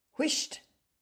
Each week, SAY IT IN IRISH features an Irish or Hiberno-English word or phrase, exploring its meaning, history and origins – with an audio recording by a native Irish speaker from Cork so you can hear how it’s pronounced.
Whisht – pronounced roughly wih-shht or hwi-shht